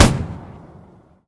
mg-shot-3.ogg